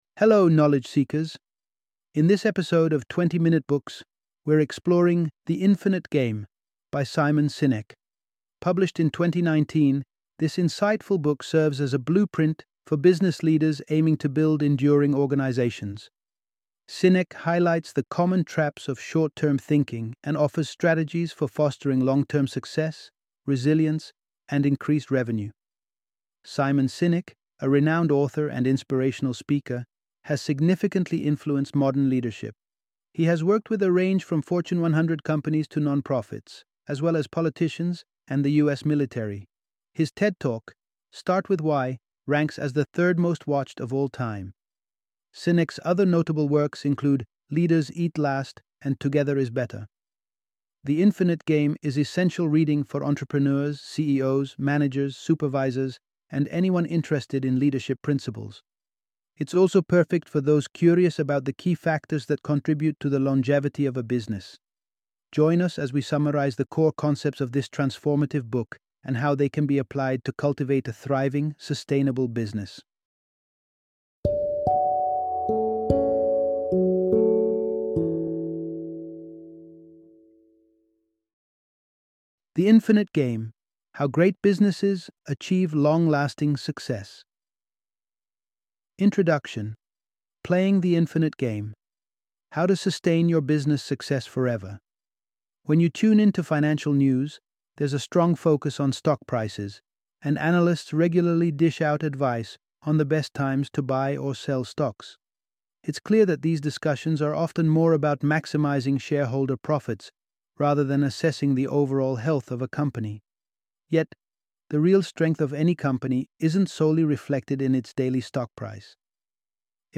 The Infinite Game - Audiobook Summary